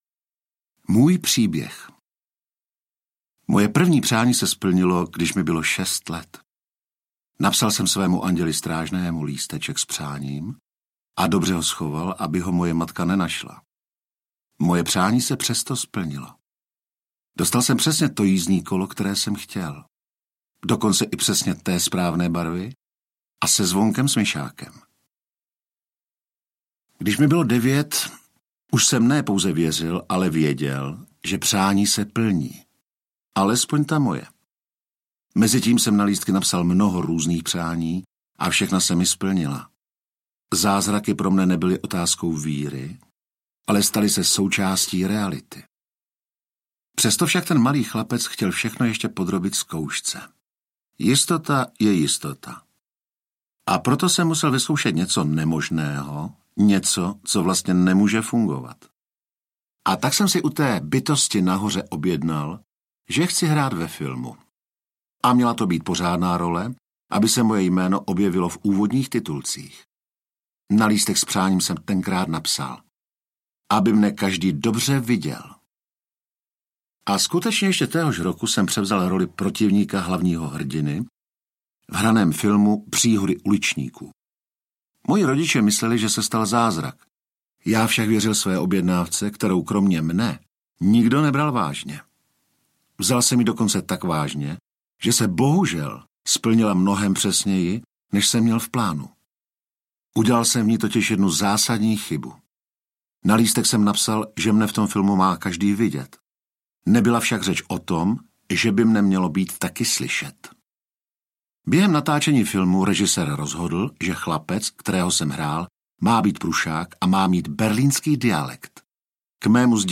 Jak si správně přát audiokniha
Ukázka z knihy